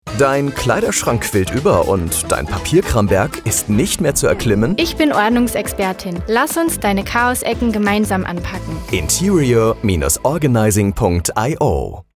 Ein Radiospot für iO I.NTERIOR O.RGANIZING
😊 Der Spot wird in der Woche vom 15.08. bis 19.08. einmal täglich im Feierabendprogramm von Antenne Bayern ausgespielt.
Funkspot_interior-organizing-12-Sek.mp3